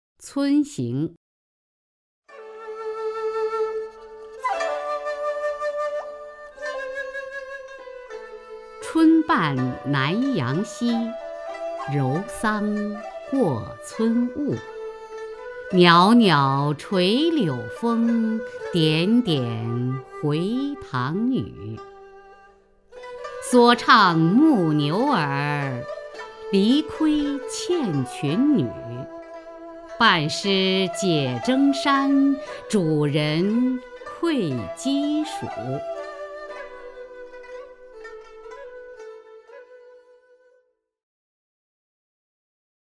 雅坤朗诵：《汉江》(（唐）杜牧) （唐）杜牧 名家朗诵欣赏雅坤 语文PLUS